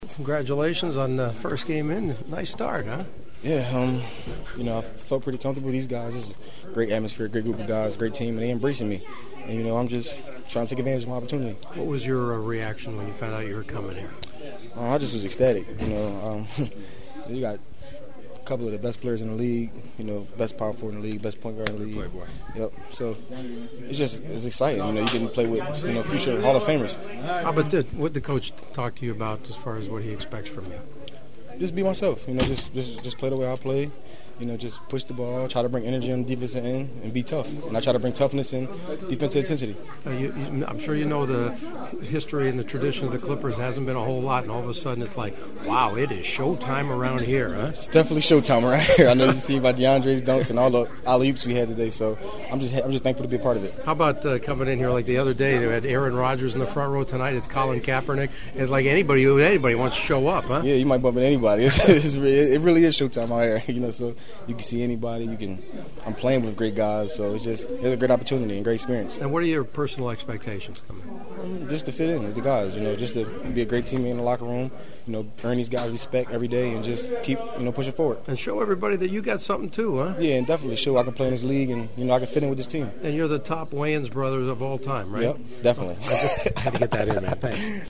The following is my postgame locker room chatter with the winning Clippers who are now an impressive 25 games over .500 before hosting Memphis on Wednesday night (which you can always hear on KFWB Newstalk 980).